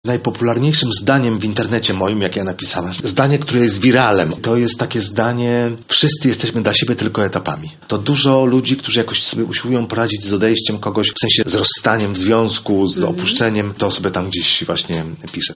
W Dzielnicowym Domu Kultury Czuby Południowe spotkaliśmy się z laureatem nagrody Nike